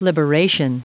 Prononciation du mot liberation en anglais (fichier audio)
Prononciation du mot : liberation